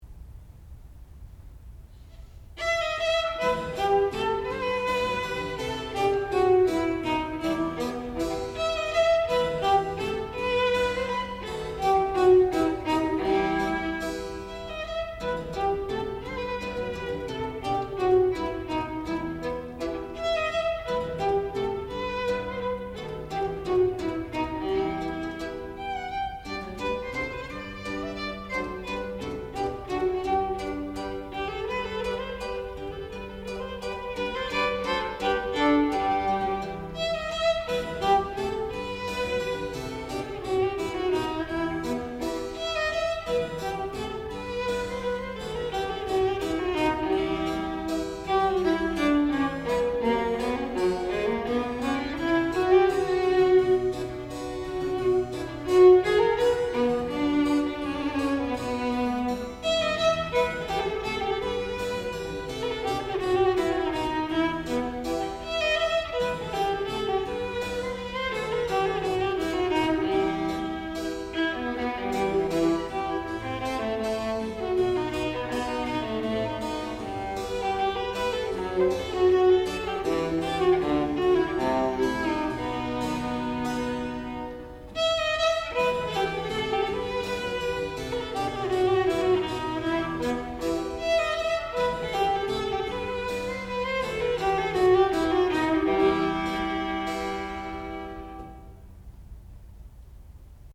sound recording-musical
classical music
Master's Recital